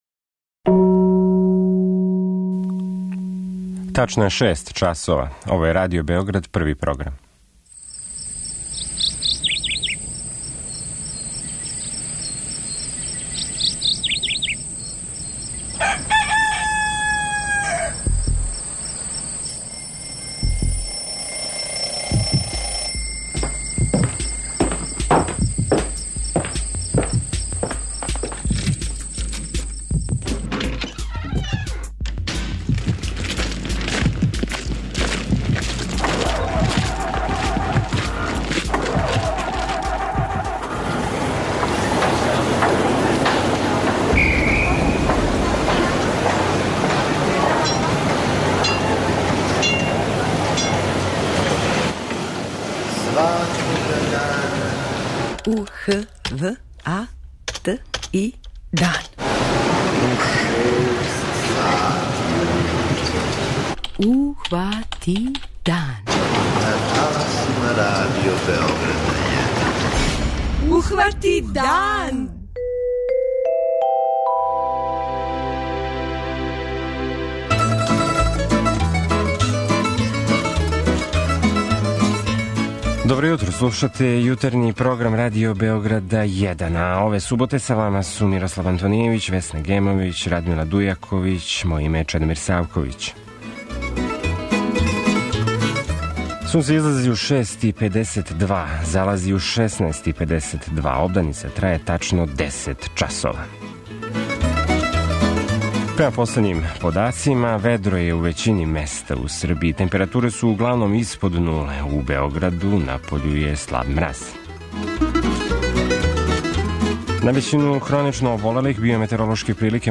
Први дан викенда резервисан је за следеће теме у нашем јутарњем програму: